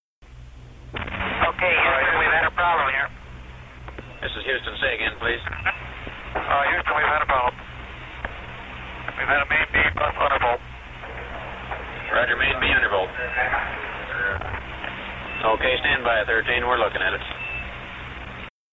“OK Houston, we’ve had a problem here…” Swigert and Lovell in turn report Apollo 13 could be in difficulties